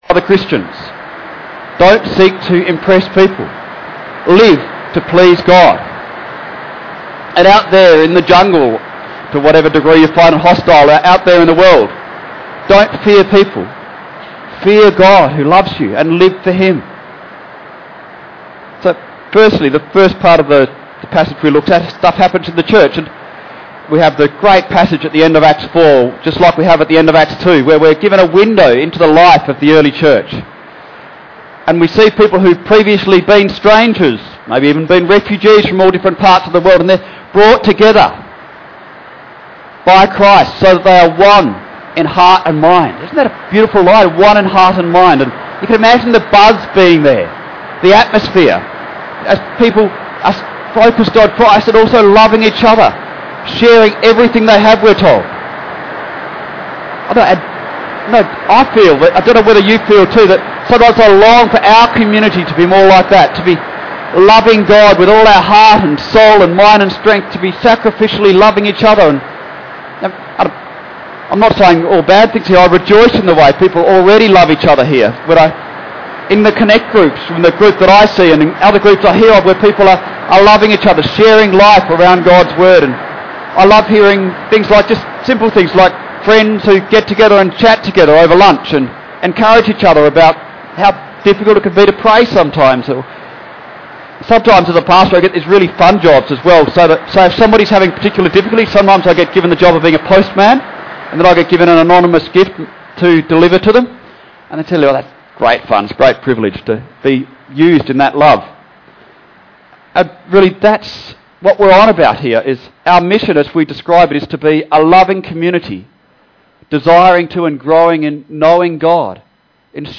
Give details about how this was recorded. Yes, the rain was heavy during this sermon, but after some hassles at the start the technical crew did a great job of managing the sound